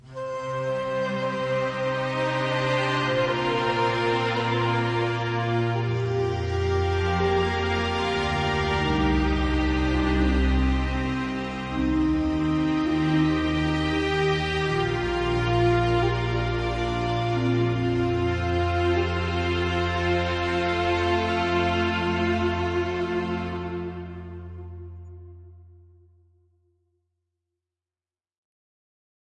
金属上的金属Harmo循环（120 BPM）
描述：打击行动，戏剧或纪录片电影。 120 BPM。
Tag: 终结者 电影 Xinematix 好莱坞 循环 打击乐 电影 金属 电影 120BPM